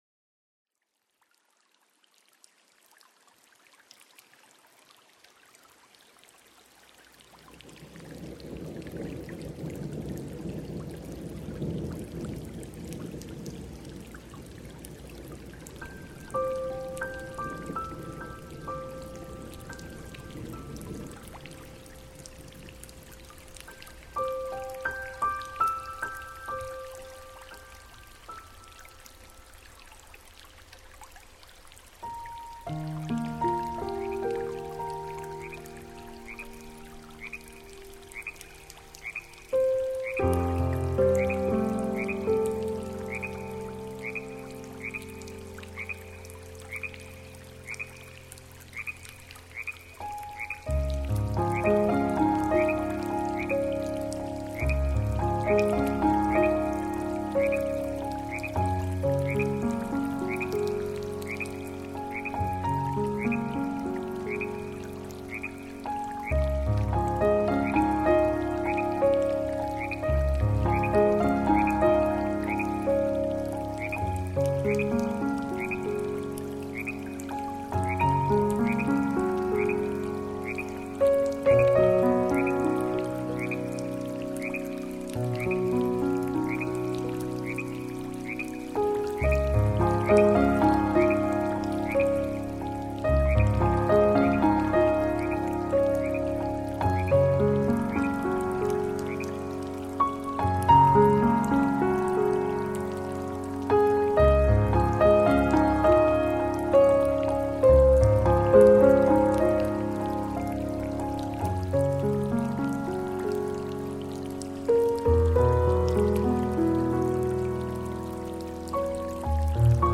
透过钢琴柔美的音色，诠释水的多样风情； 森林的雨声滴答作响，涓涓细流的低声吟唱， 瀑布流泻而下的银线奇景...